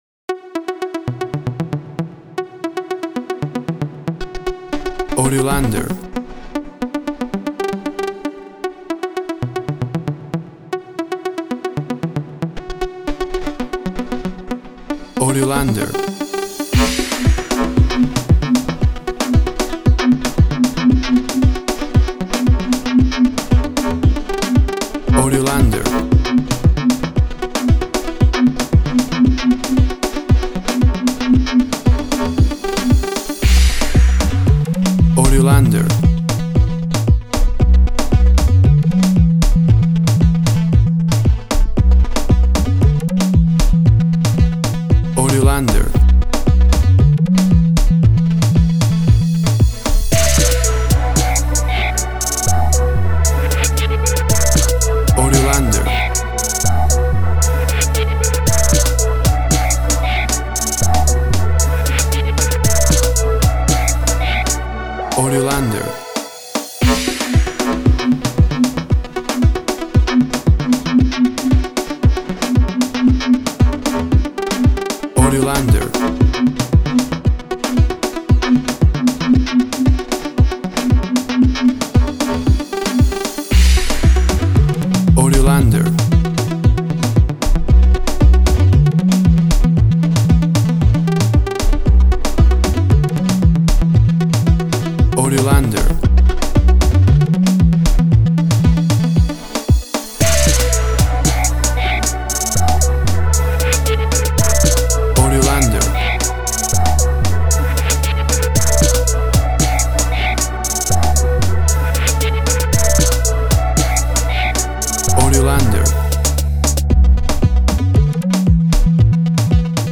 Classic reggae music with that skank bounce reggae feeling.
WAV Sample Rate 16-Bit Stereo, 44.1 kHz
Tempo (BPM) 116